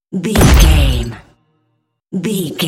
Dramatic hit deep debris
Sound Effects
Atonal
heavy
intense
dark
aggressive